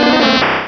pokeemerald / sound / direct_sound_samples / cries / oddish.aif
-Replaced the Gen. 1 to 3 cries with BW2 rips.
oddish.aif